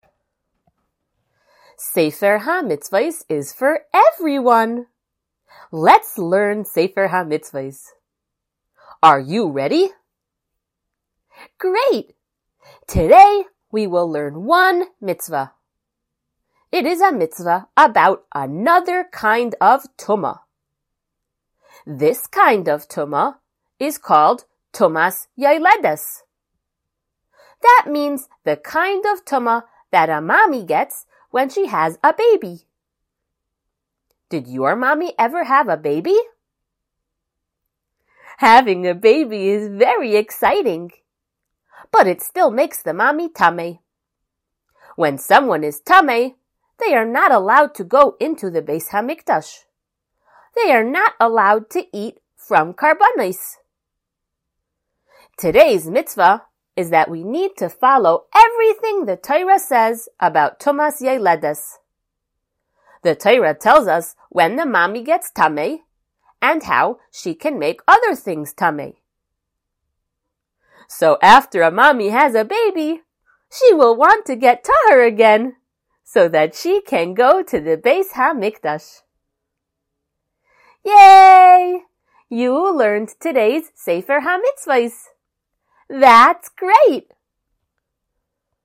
Color Shiur #214!
SmallChildren_Shiur214.mp3